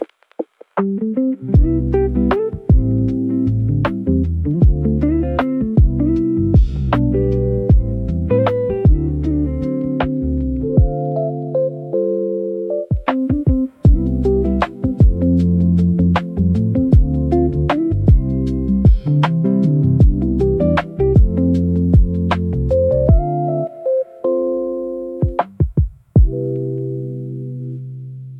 温かく落ち着いた昼の空気感。
Lo-fi Neo Soul 居心地重視